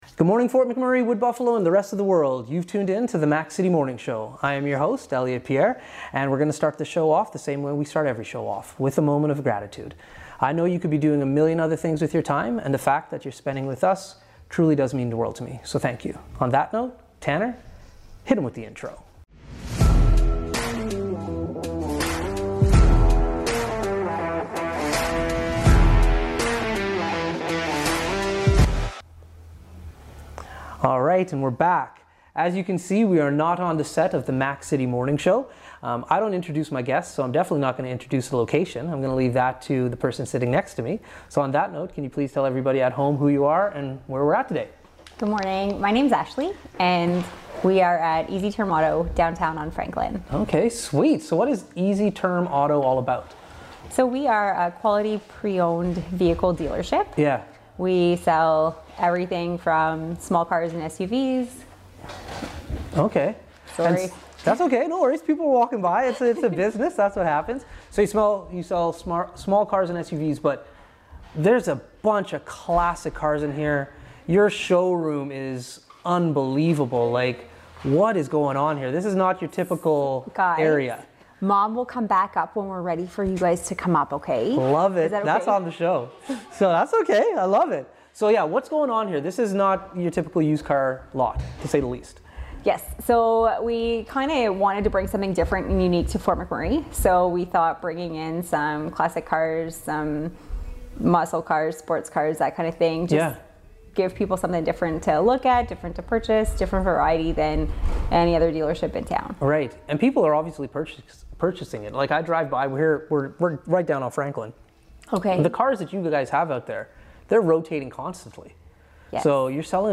Once again, the Morning Show is on location at Easy Term Auto, located on Franklin Ave.